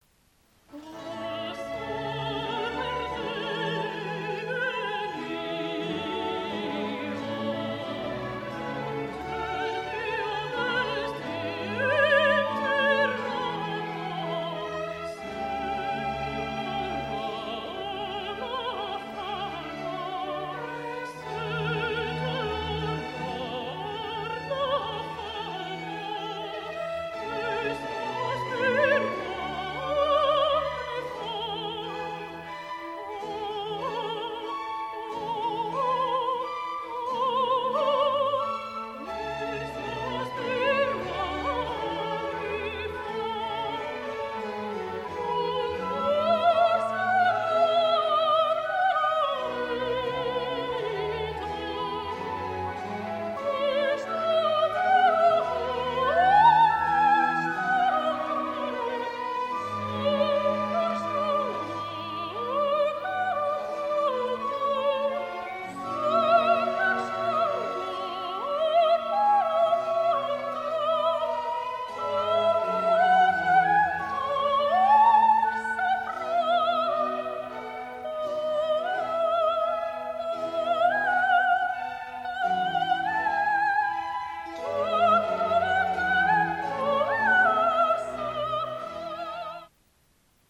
Die Arie